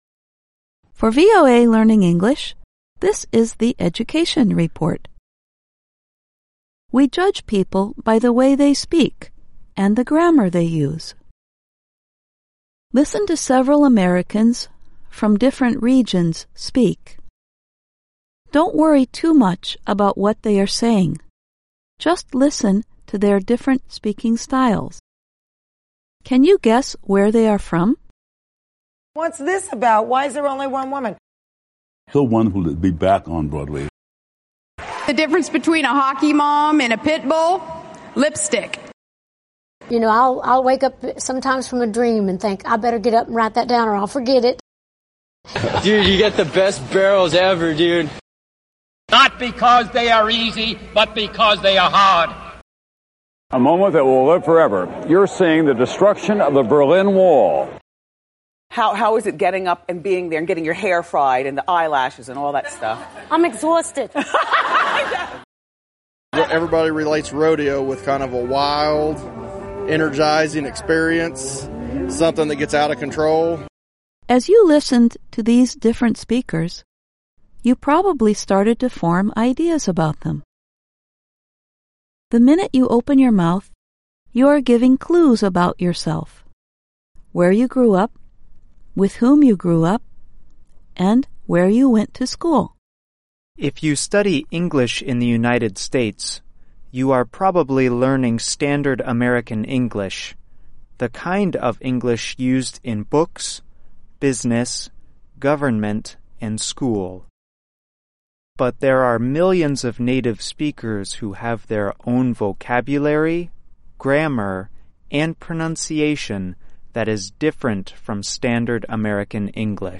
Listen to several Americans from different regions speak. Don’t worry too much about what they are saying, just listen to their different speaking styles.
George W. Bush was speaking with a working class Southern accent, even though he grew up in New England.